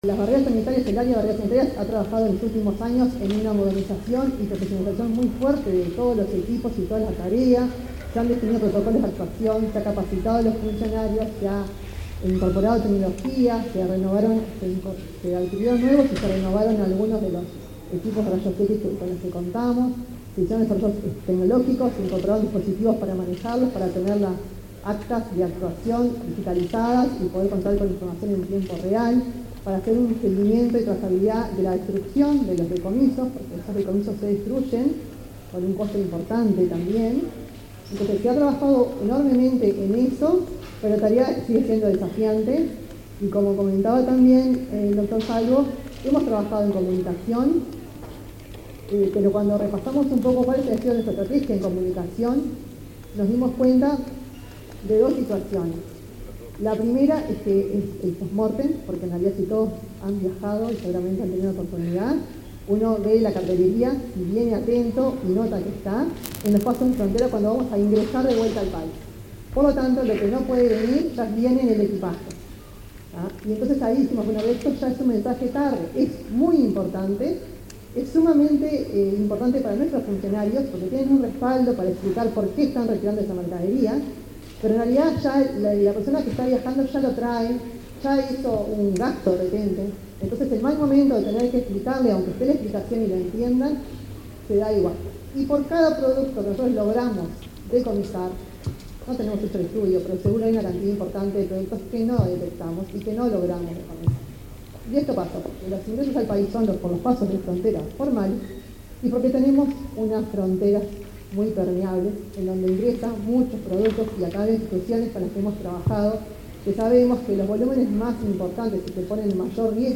Palabras de autoridades del MGAP
Palabras de autoridades del MGAP 21/06/2023 Compartir Facebook X Copiar enlace WhatsApp LinkedIn La Dirección General de Bioseguridad e Inocuidad Alimentaria, del Ministerio de Ganadería, Agricultura y Pesca (MGAP) realizó el lanzamiento de la campaña de comunicación "Evitemos que pase". La directora de la dependencia, Virginia Guardia, y el titular de la cartera, Fernando Mattos, destacaron la importancia de la iniciativa.